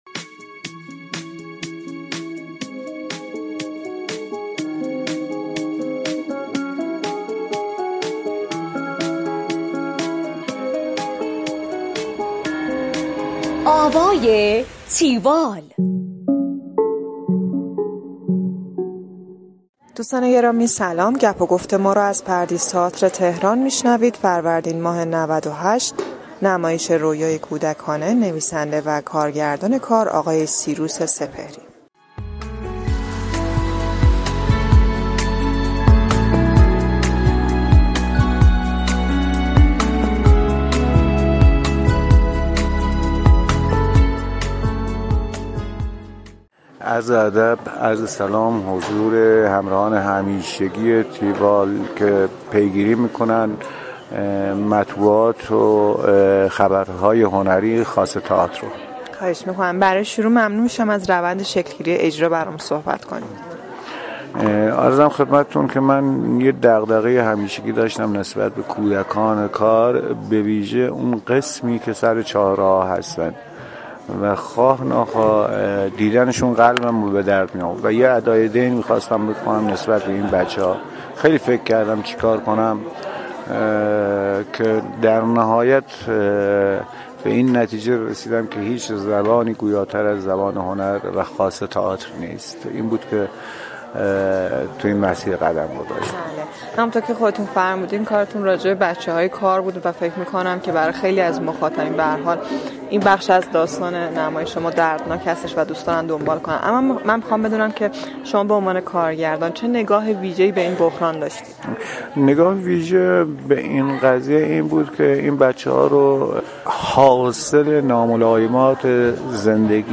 گفتگو کننده: